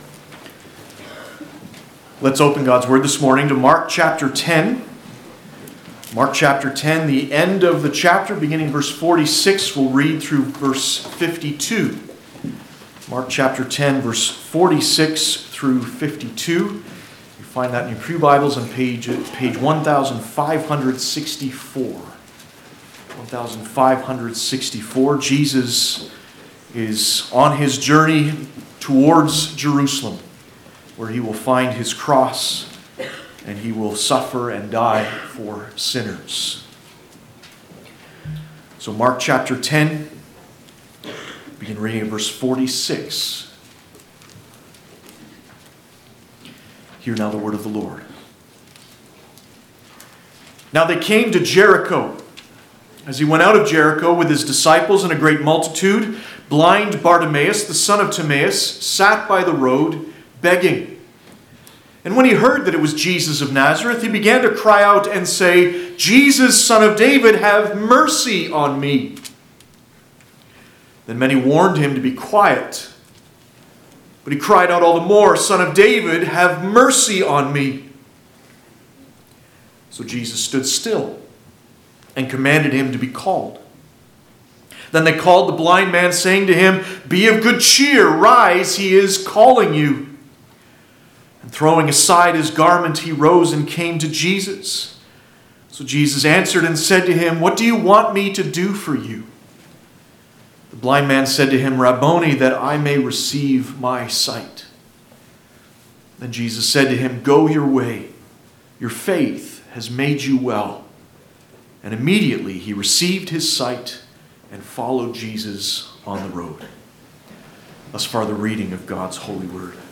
Passage: Mark 10:46-52 Service Type: Sunday Morning